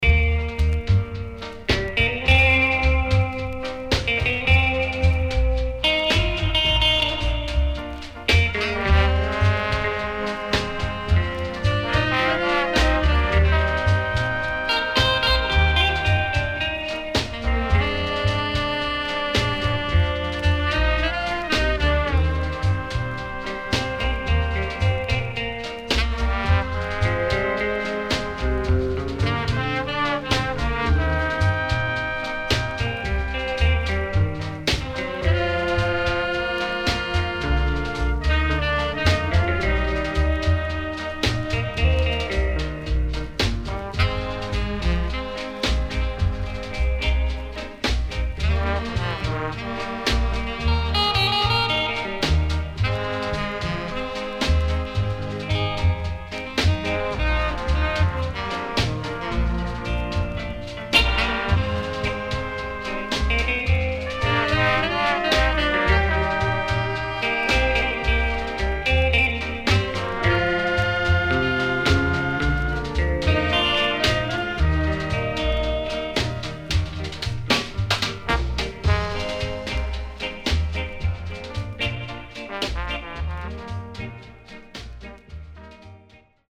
SIDE A:プレス起因で所々ノイズ入りますが良好です。